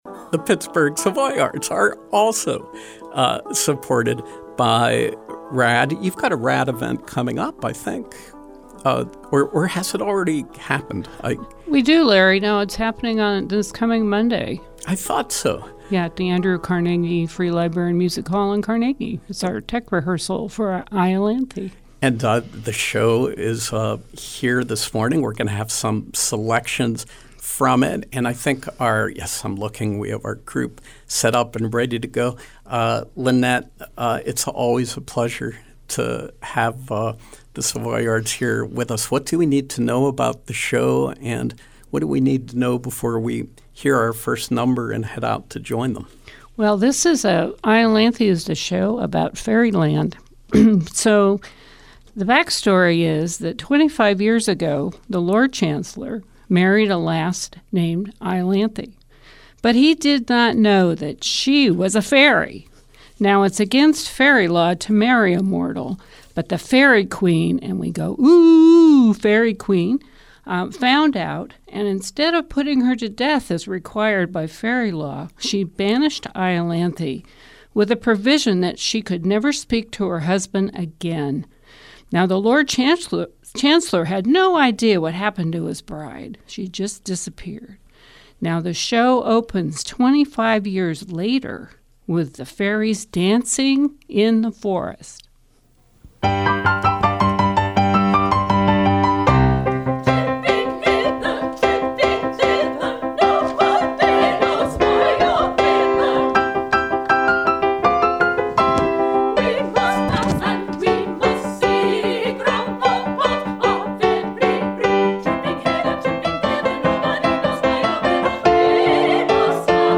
Live Music: Iolanthe, Pittsburgh Savoyards